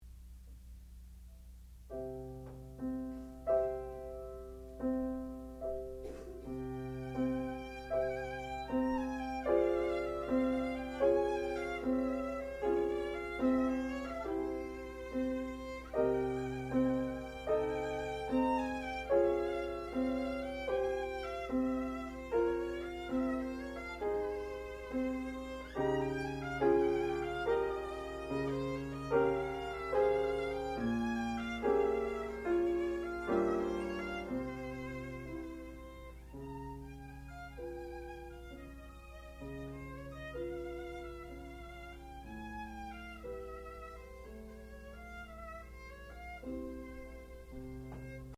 sound recording-musical
classical music
violin
piano
Advanced Recital